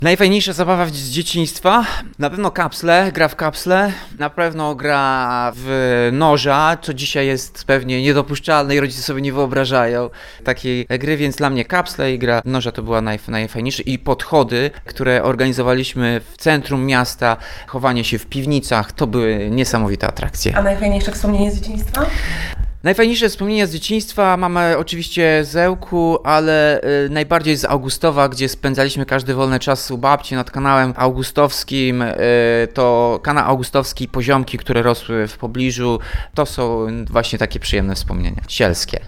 Wspomina Tomasz Andrukiewicz – prezydent Ełku.